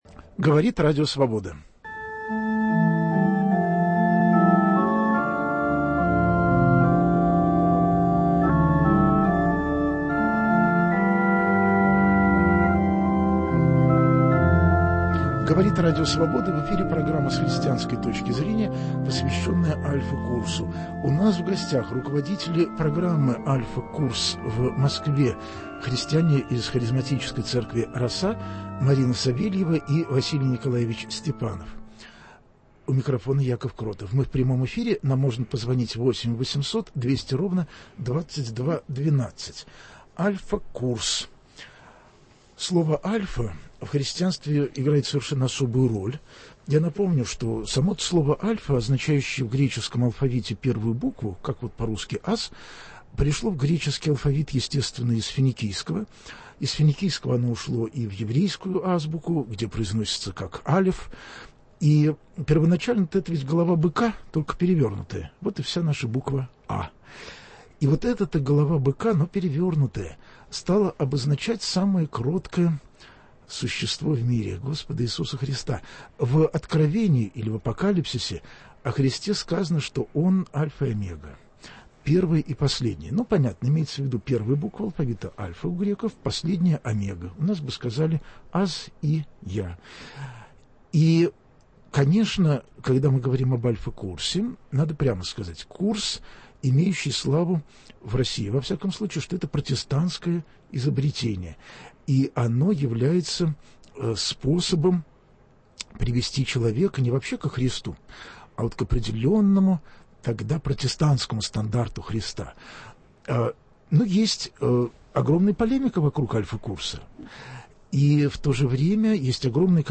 В гостях у программы будут преподаватели "Альфа-курса". Разговор пойдёт о том, как можно помочь прийти к вере во Христа, как в таком интимном деле вообще возможны какие-то "курсы".